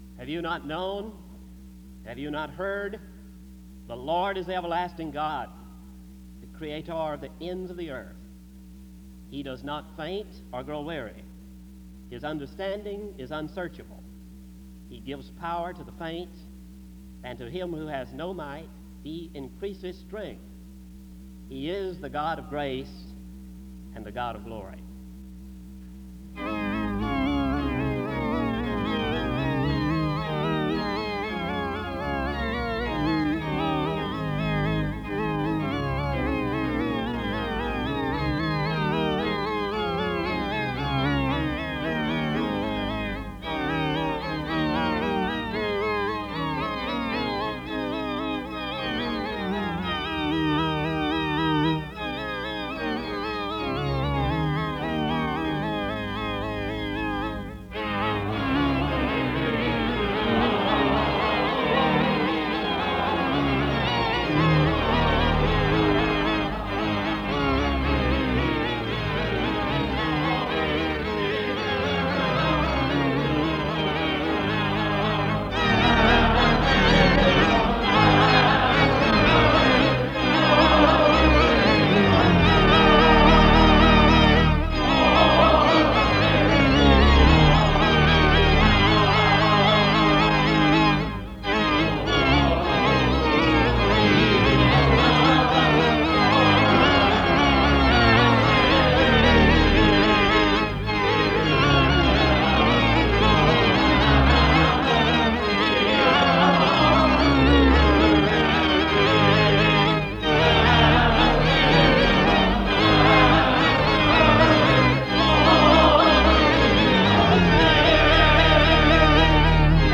SEBTS Convocation